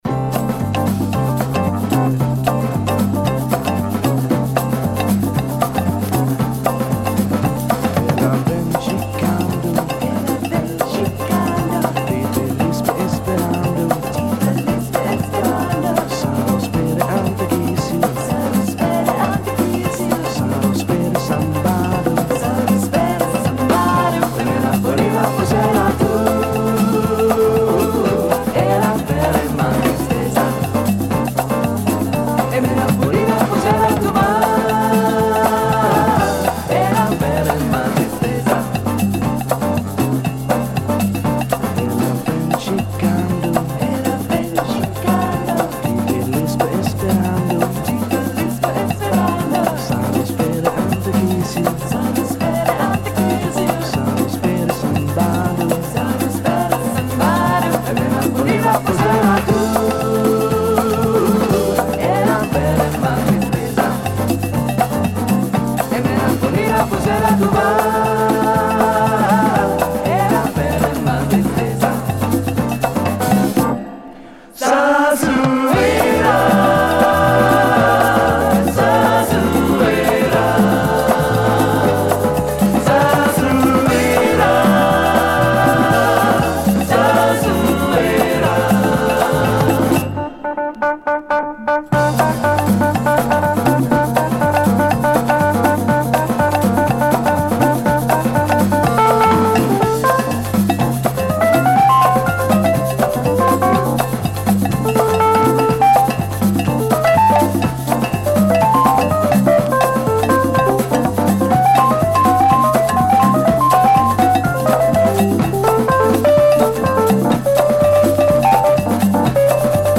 a lovely Swedish version
Do you dig the Fender Rhodes in latin jazz ?
Category: Jazz